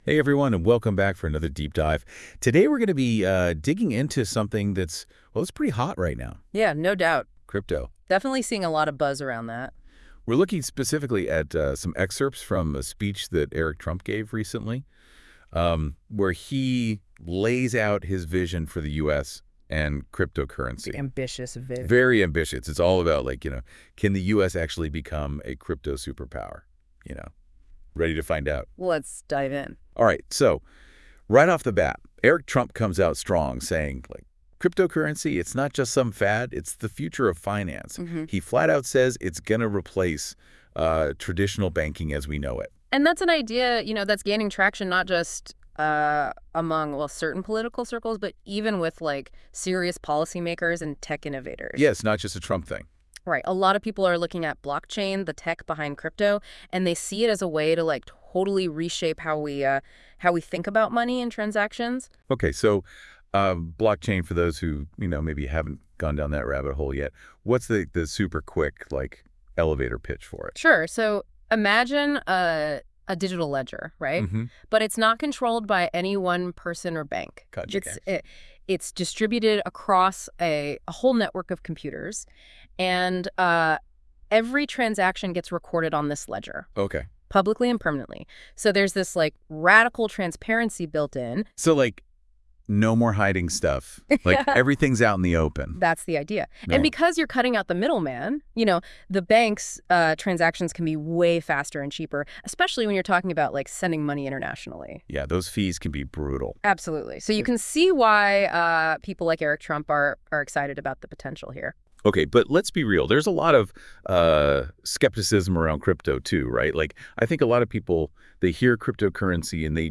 Podcast Discussion: Deep Dive Into This Article.